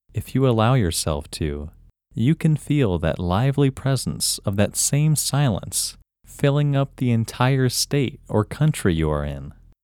WHOLENESS English Male 10
WHOLENESS-English-Male-10.mp3